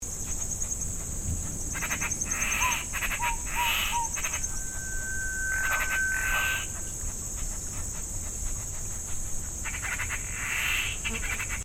The sand road ('Bunker track') through Panti forest reserve. At this spot we had both Fluffy-backed Tit-Babbler and Malaysian Rail-Babbler. We made a 1 minute sound recording of both species together.
The rail-babbler is the whistling note on one pitch, the other two sounds are from the tit-babbler.
FluffybackedTitBabbler_and_MalaysianRailBabbler_Panti243Sel.mp3